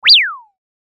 03-1 ピュー2 synth1
ピュー